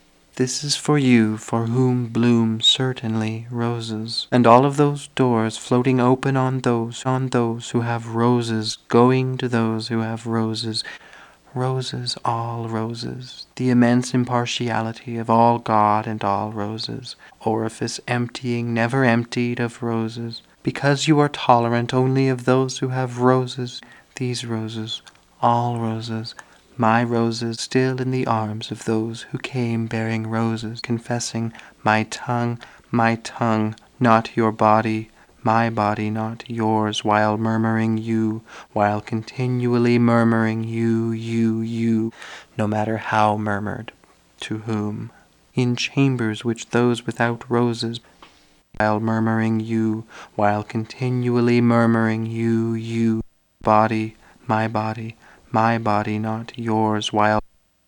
Source: Random text chosen from 17 segments (6:36-8:58)